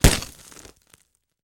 Звуки падения, грохота
Жмяк и на полу